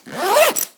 action_open_backpack_0.ogg